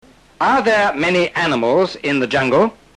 Recuerda que a partir de esta lección los audios son más veloces.